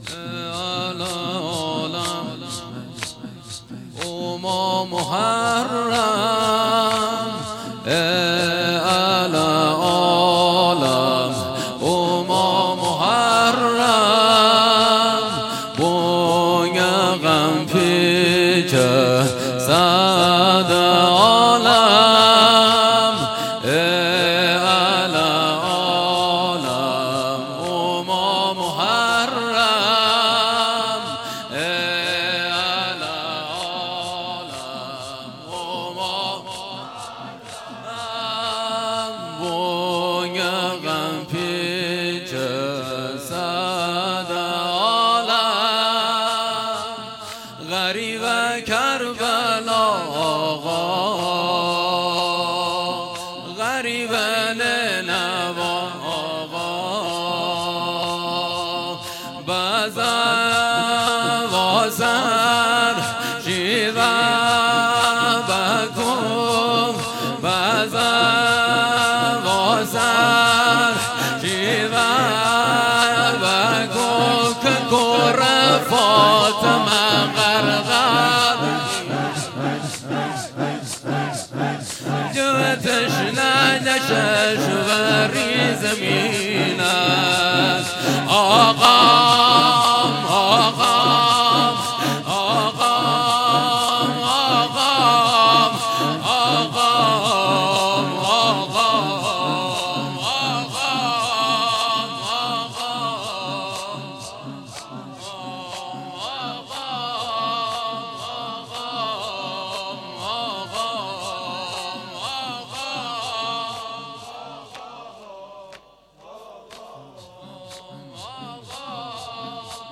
مداحی لری